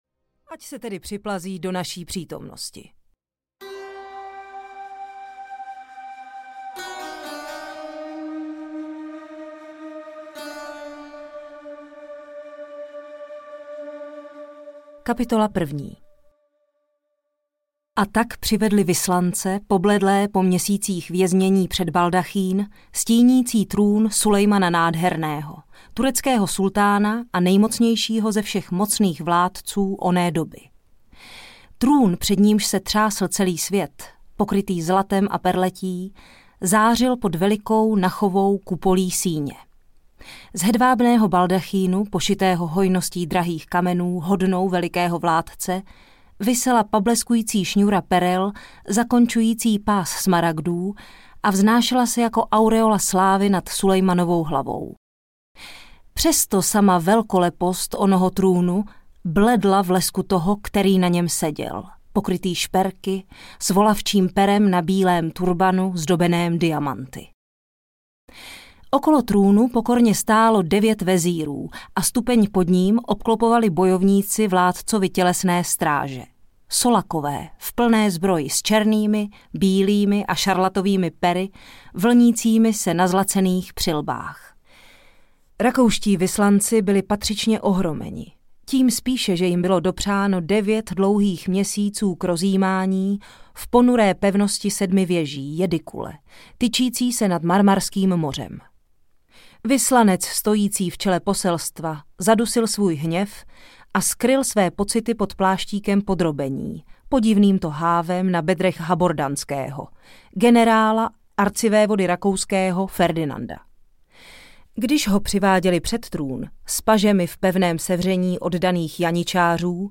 Rudá Soňa - Stín supa audiokniha
Ukázka z knihy
• InterpretZuzana Kajnarová